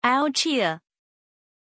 Pronunția numelui Alícia
pronunciation-alícia-zh.mp3